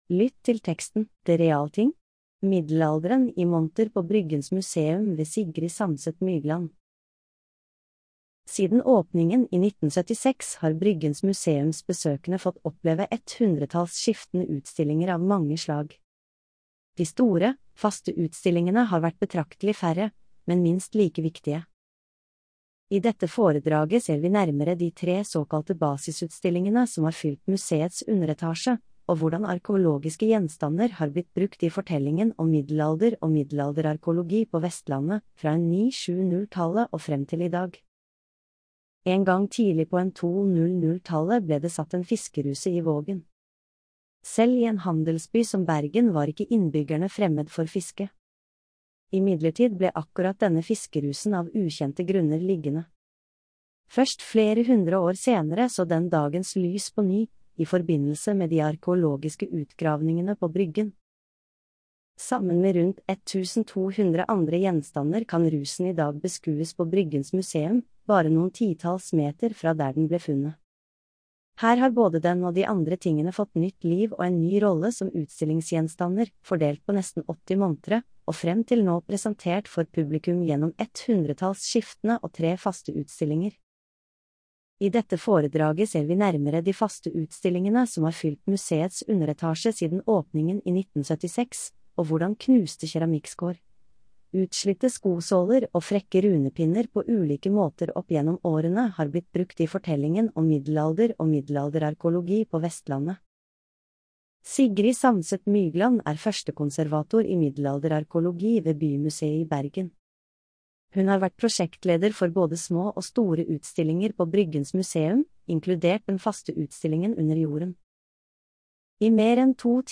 Populærvitenskapelige foredrag.